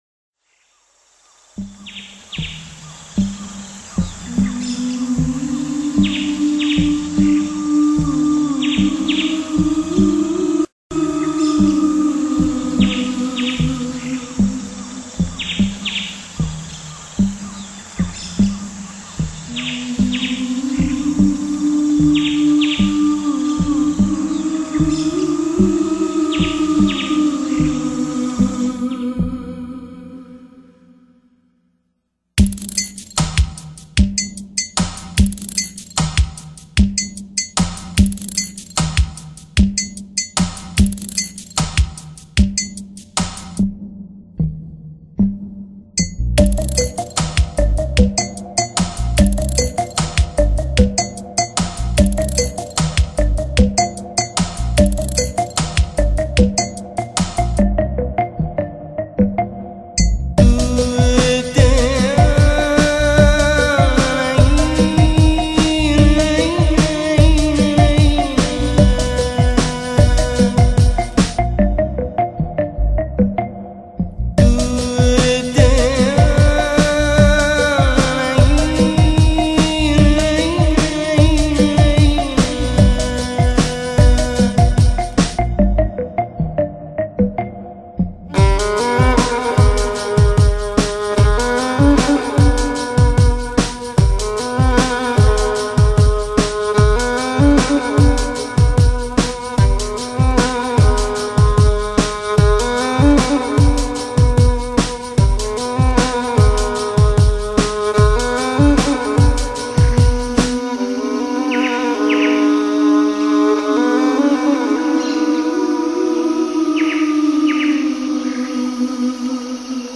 音乐类型: Newage
太阳的余辉已渐渐淡去，低沉的号角，响彻整个森林。
尺八，在若隐若现中扬起，依然那样的柔美，委婉。